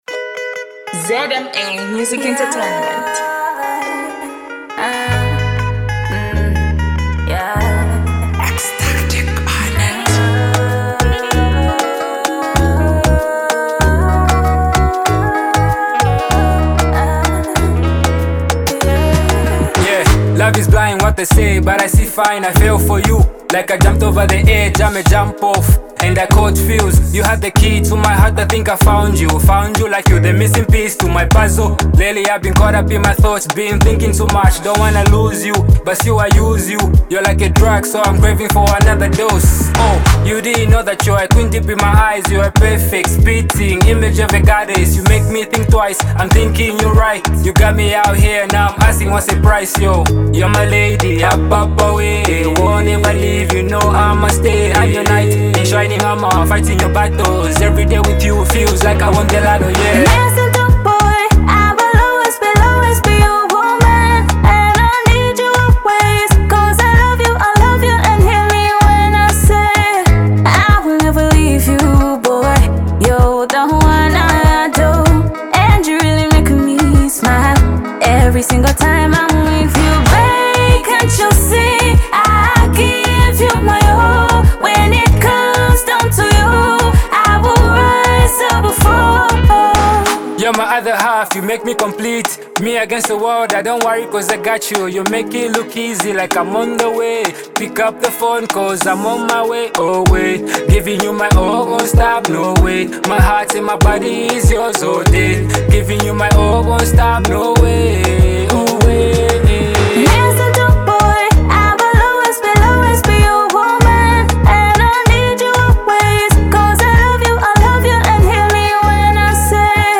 songstress
Afrobeat